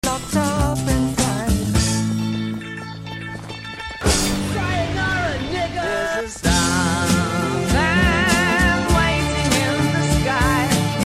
The time someone’s kill sound matched with the song I was listening too